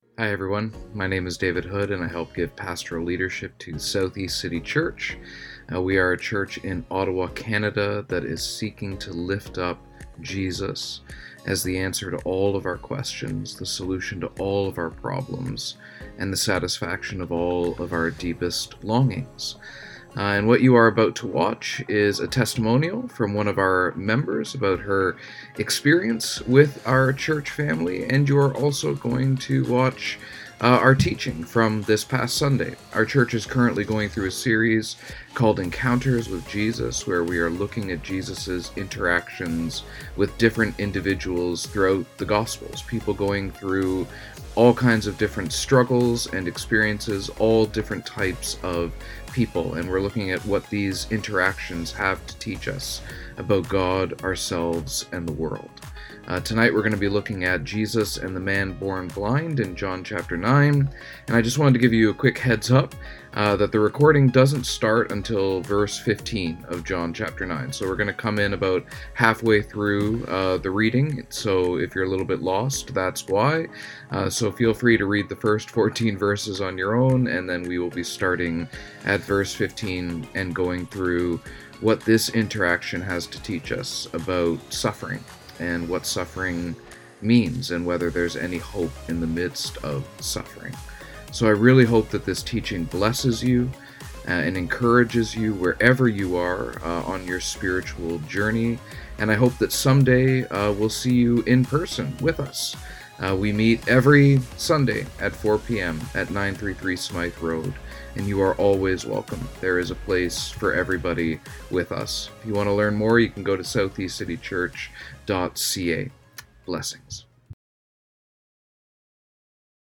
2024 Jesus and Nathanael Preacher